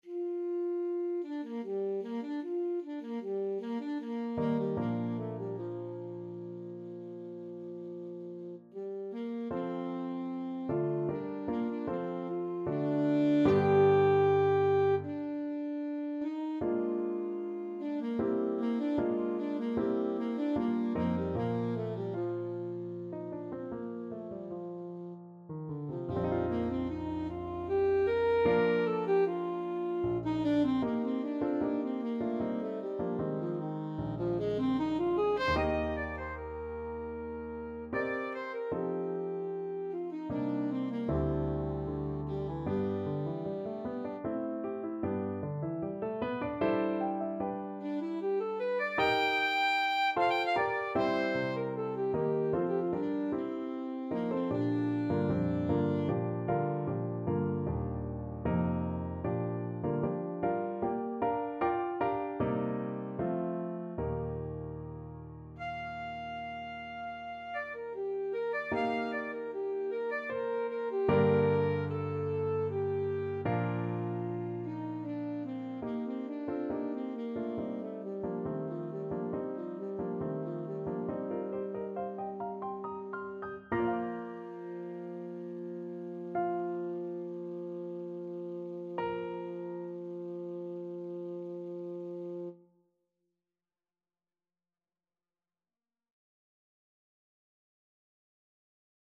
Free Sheet music for Alto Saxophone
Alto SaxophonePianoAlto Sax (Gb high)
Trs calme et doucement expressif =76
3/4 (View more 3/4 Music)
Classical (View more Classical Saxophone Music)